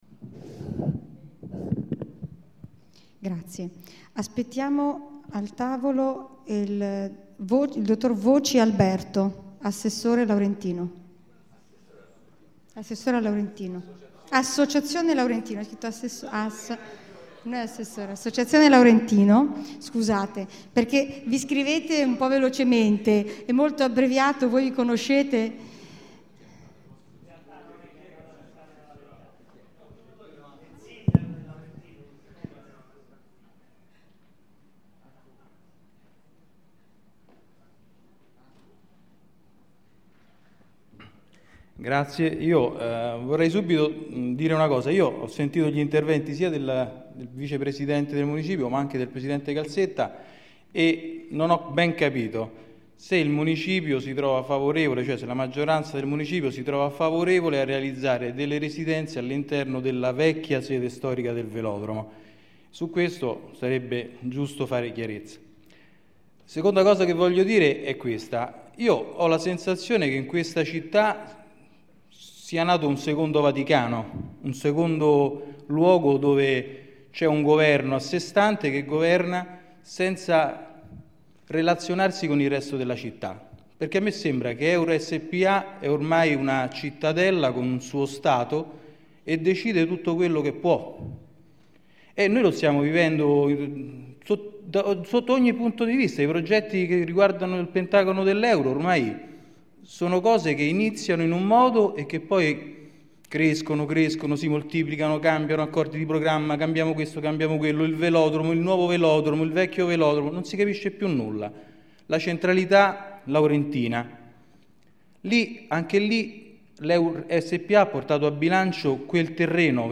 Assemblea partecipativa Ex VelodromoRegistrazione integrale dell'incontro svoltosi il 21 luglio 2011 presso l'Auditorium dell'Istituto "Massimiliano Massimo".